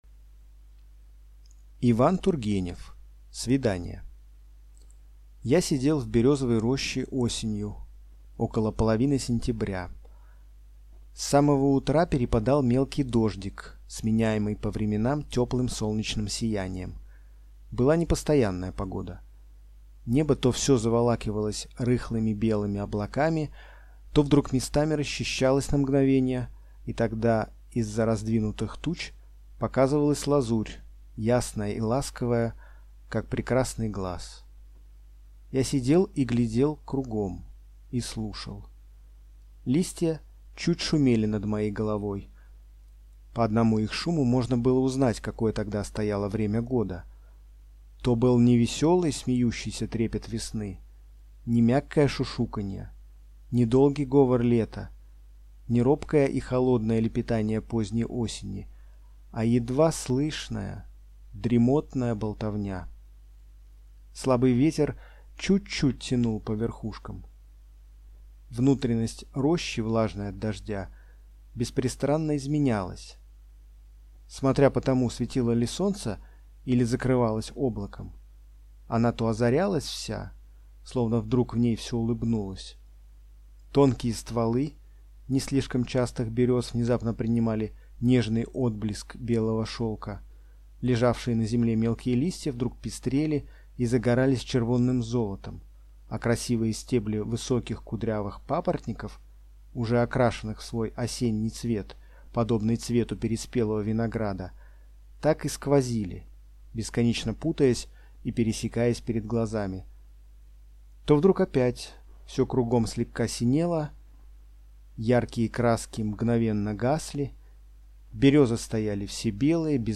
Аудиокнига Свидание | Библиотека аудиокниг